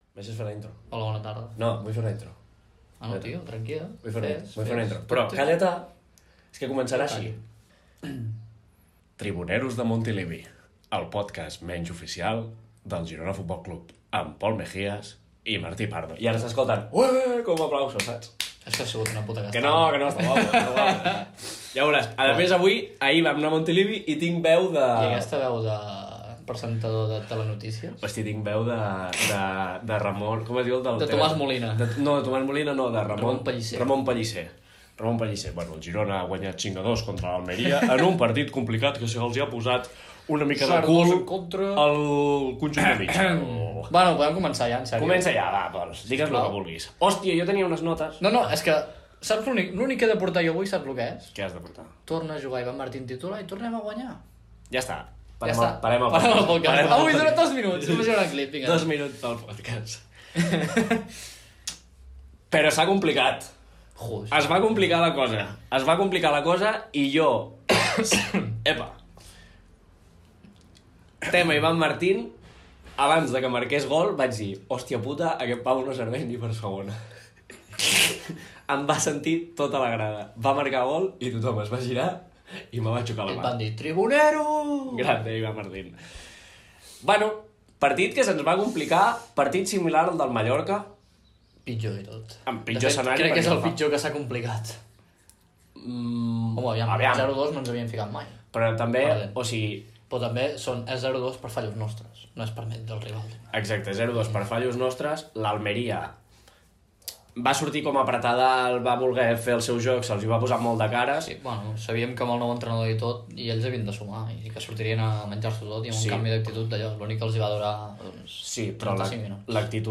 Presentació i diàleg sobre el partit de la lliga masculina de futbol de primera divisió entr el Girona Futbol Club i l'Almeria (que va acabar amb el resultat de 5 a 2)
Esportiu